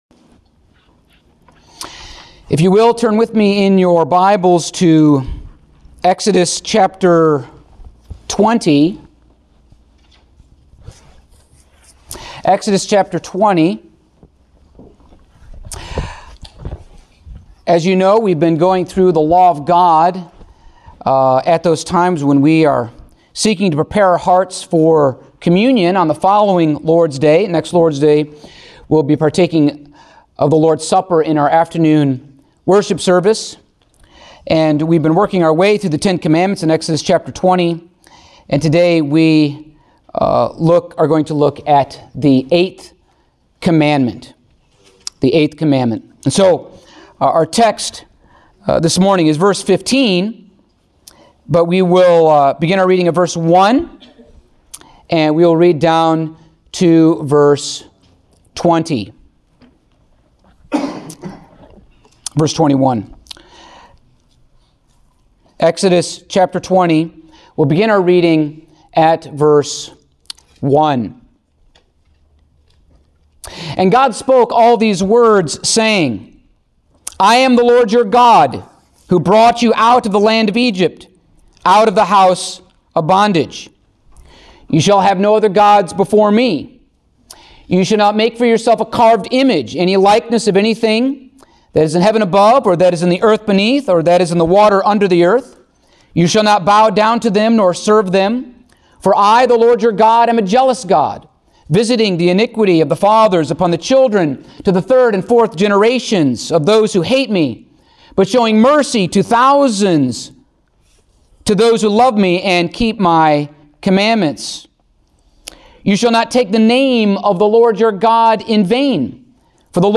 Passage: Exodus 20:15 Service Type: Sunday Morning